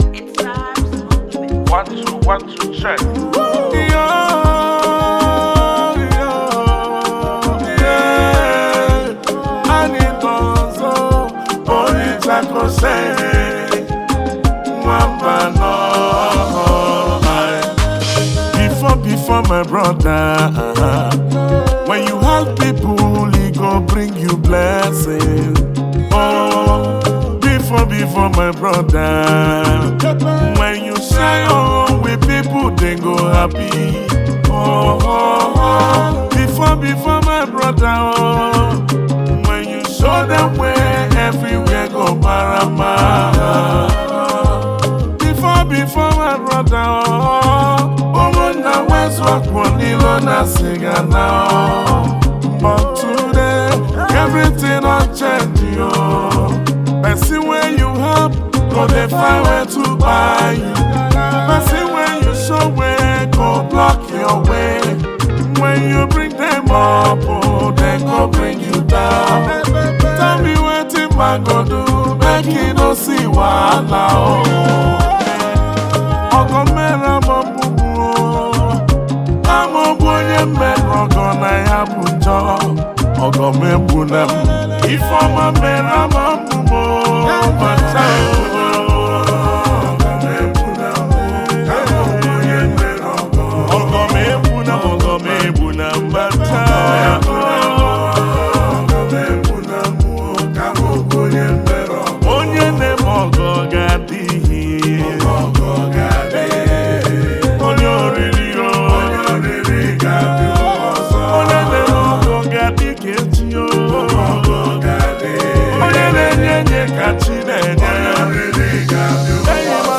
Home » Bongo » Highlife » Ogene